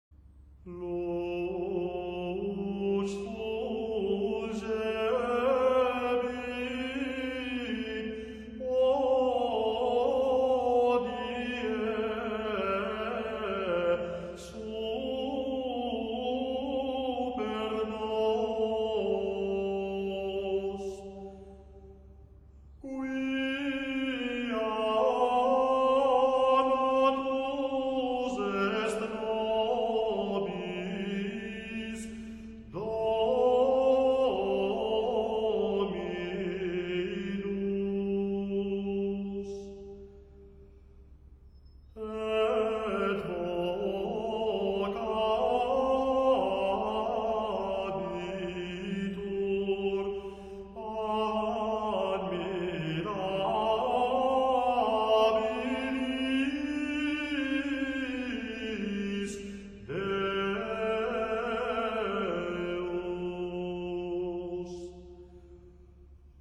Амвросианское пение
Запись 1989 г., Франция.